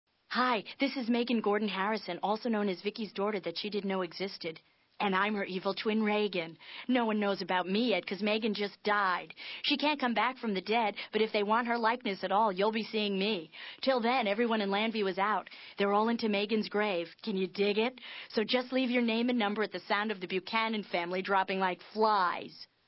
At The Sound Of The Beep... Soap Suds
Because the cassette these recordings were archived from was not properly labeled.